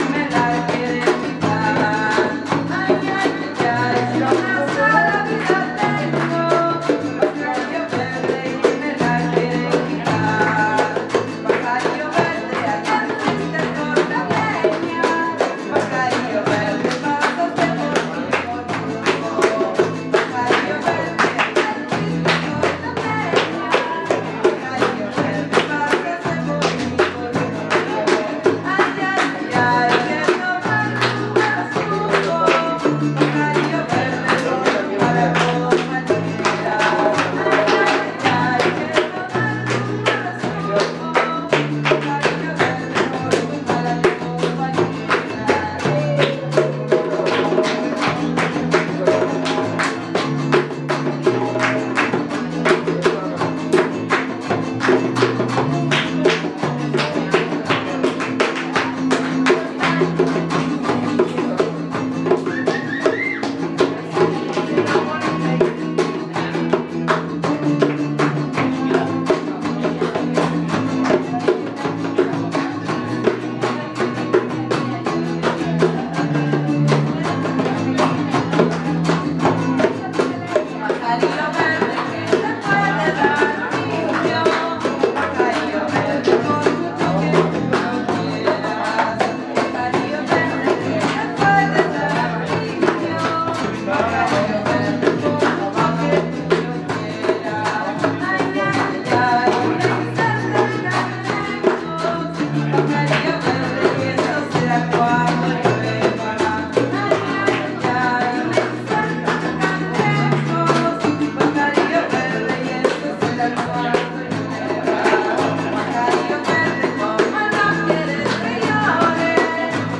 Monday Jam